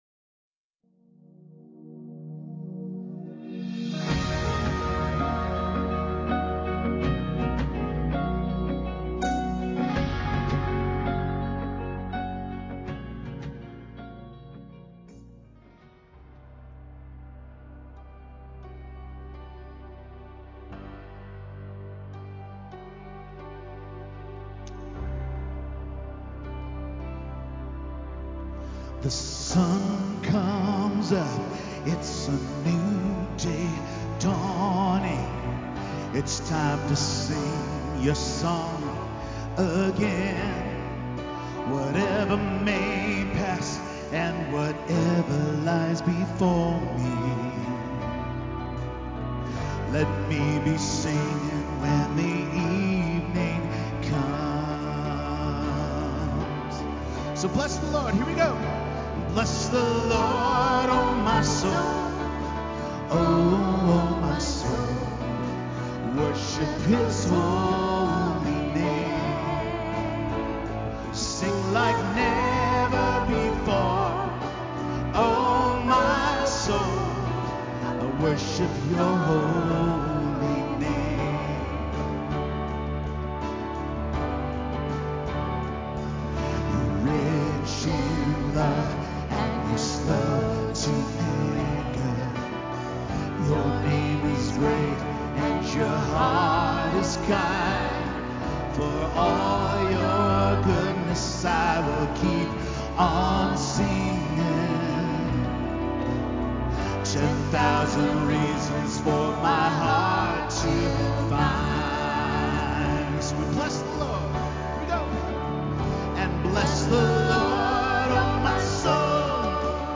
Dive into a transformative sermon exploring the Apostle Paul’s teachings in Romans, highlighting the essence of the gospel as centered on Jesus Christ.